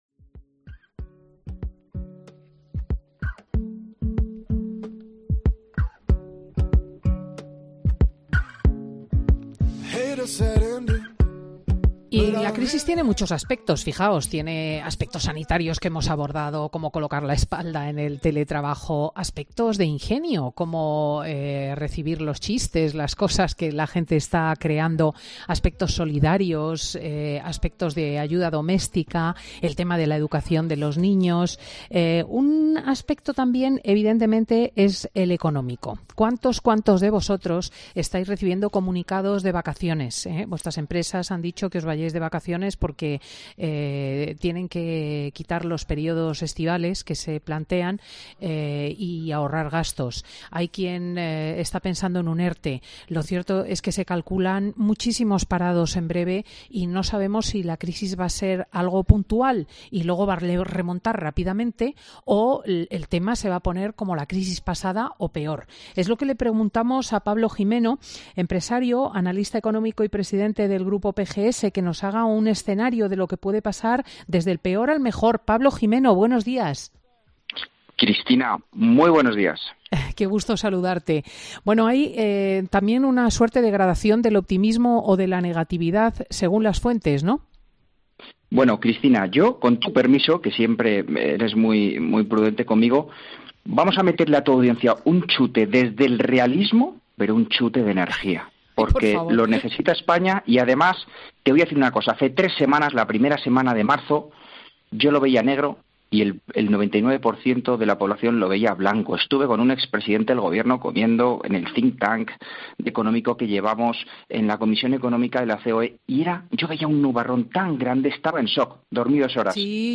El empresario y analista económico anima a los trabajadores y empresas a no tirar la toalla y mantener la esperanza